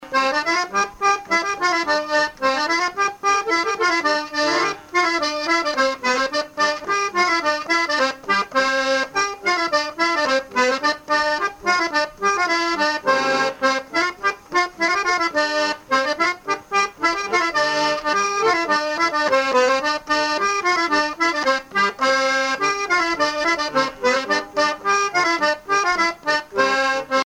Chants brefs - A danser
danse : sicilienne
musique à danser à l'accordéon diatonique
Pièce musicale inédite